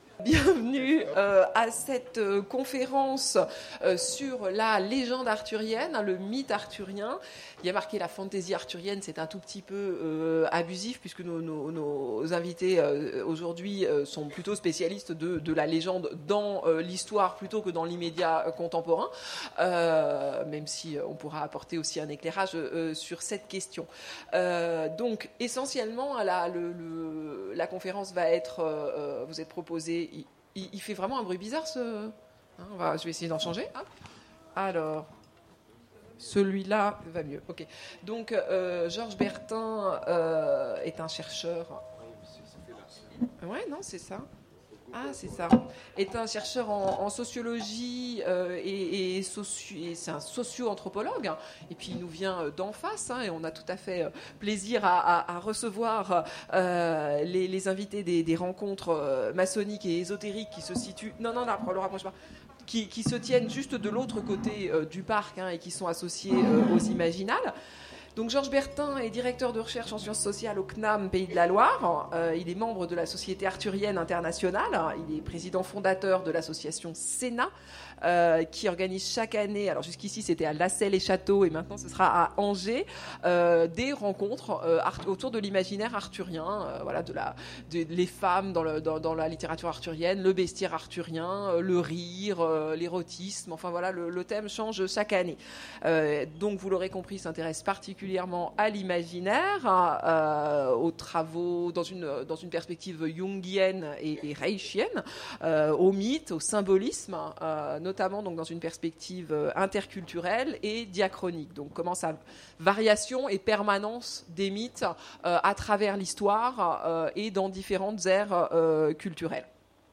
Mots-clés Arthur Conférence Partager cet article